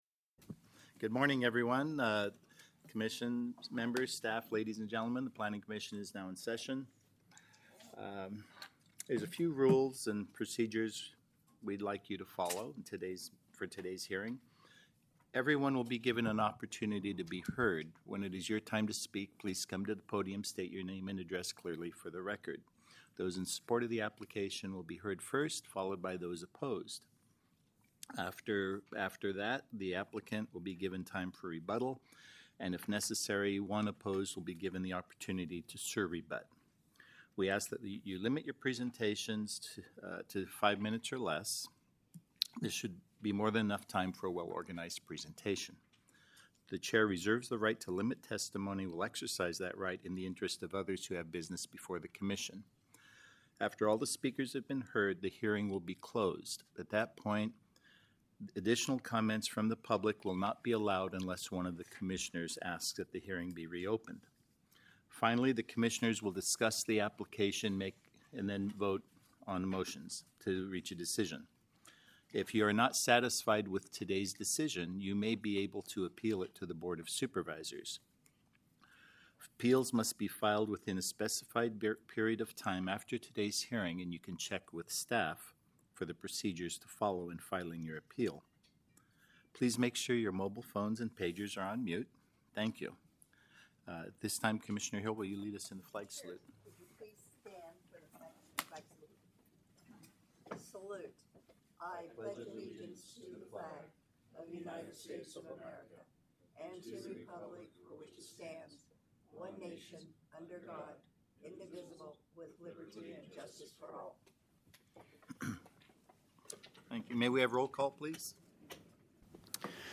October 24, 2024 Fresno County Planning Commission Hearing